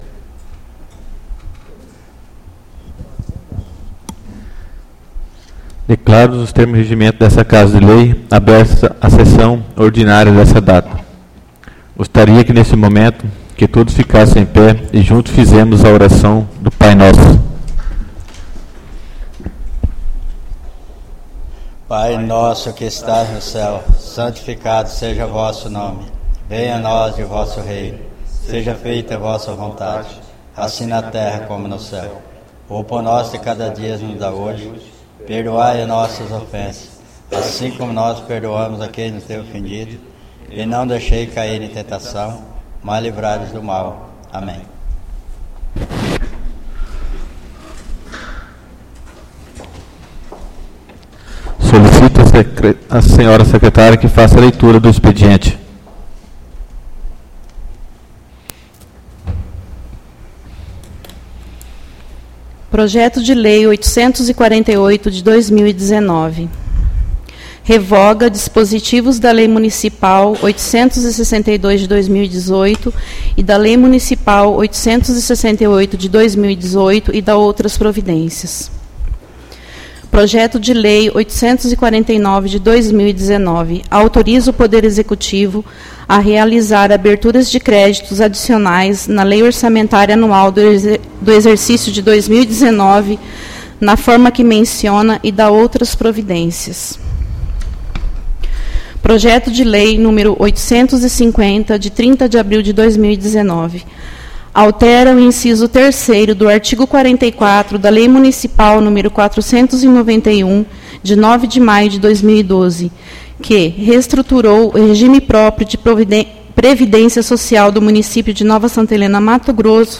Audio da Sessão Ordinária 07/05/2019